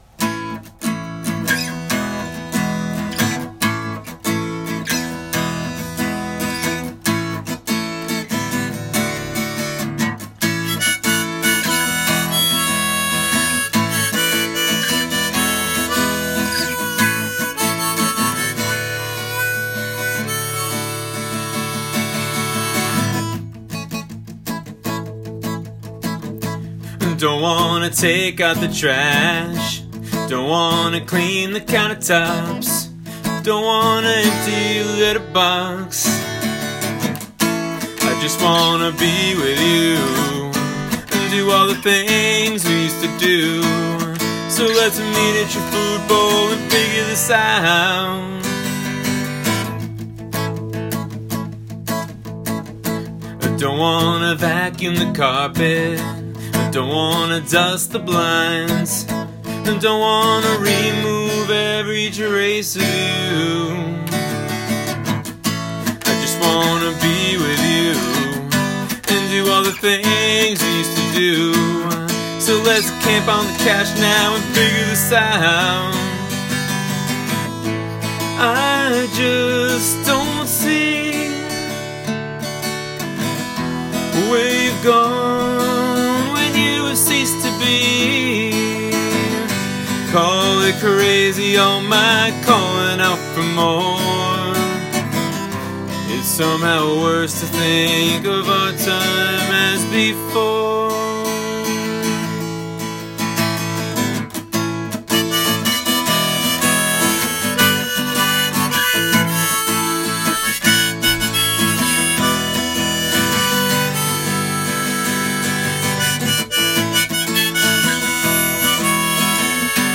[Acoustic]